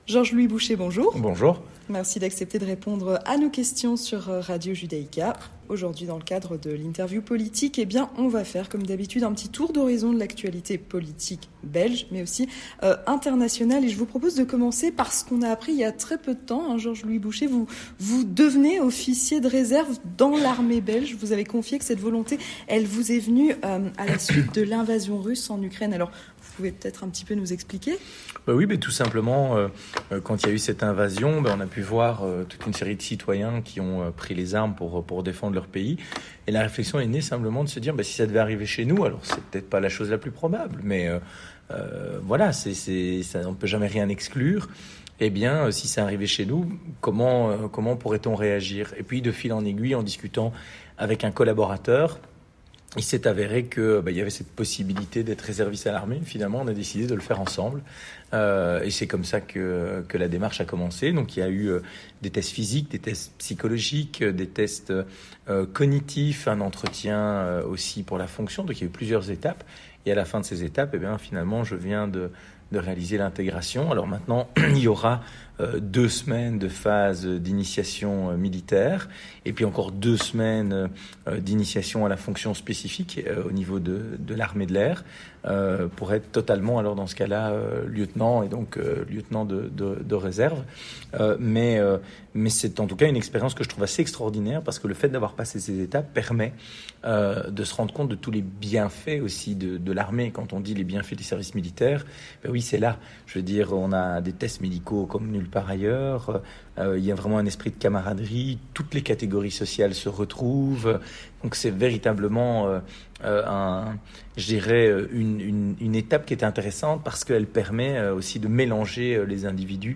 L'interview politique avec George-Louis Bouchez (14/04/2023)
Avec George-Louis Bouchez, président du MR